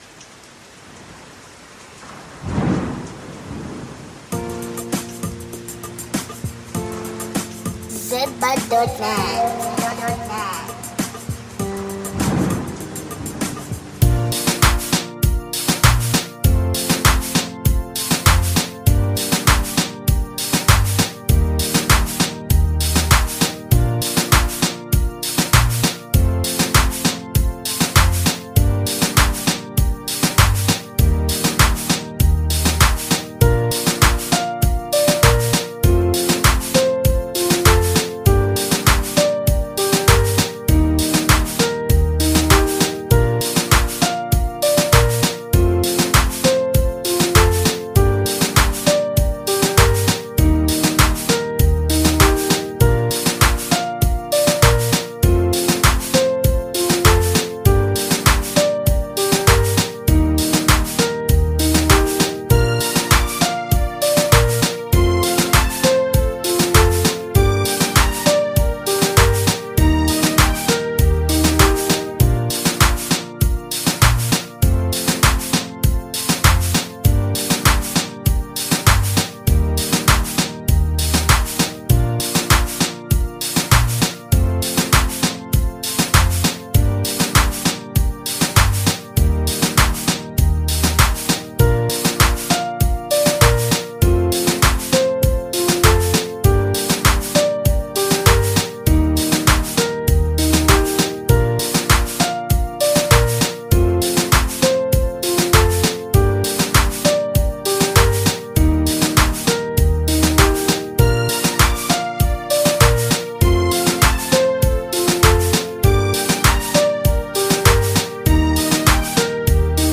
catchy and upbeat
The mix of mellow guitar riffs and a steady beat gives